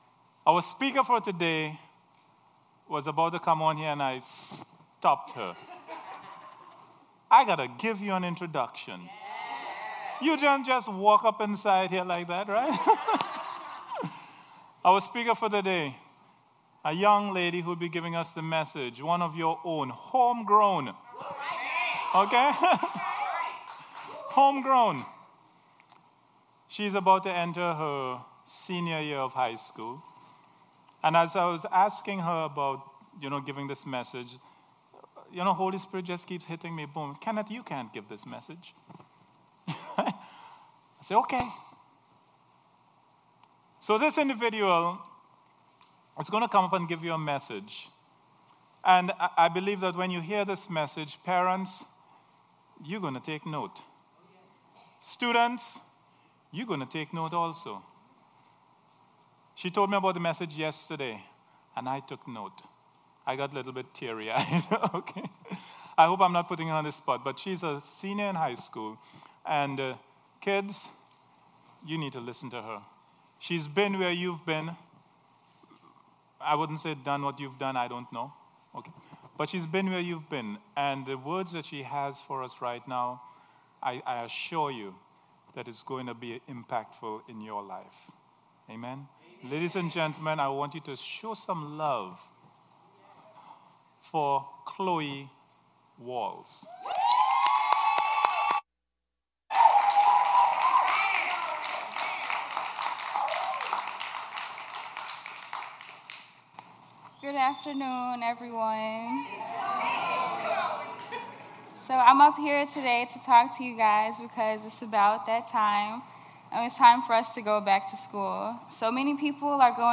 Worship Service 8/13/17